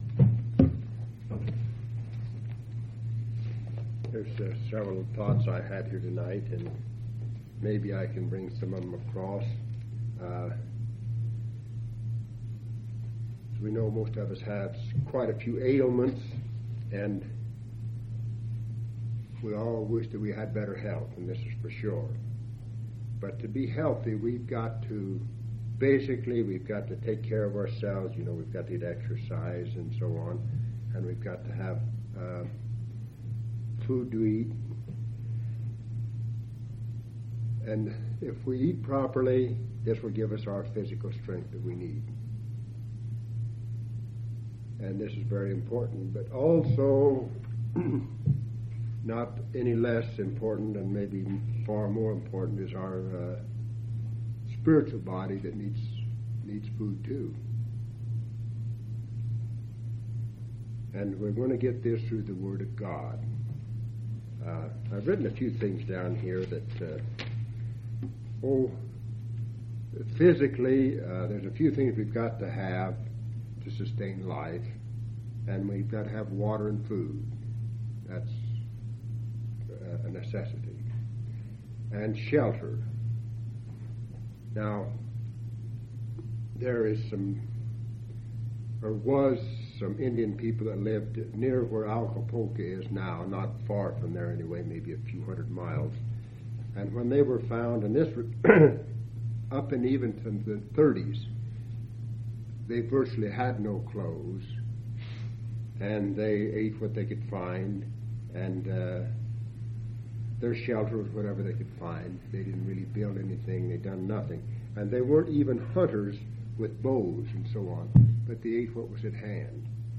1/27/1985 Location: Grand Junction Local Event